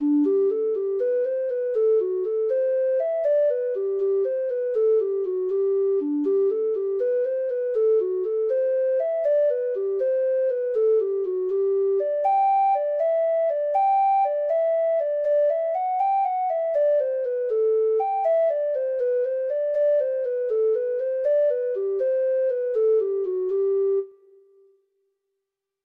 Treble Clef Instrument version
Traditional Music of unknown author.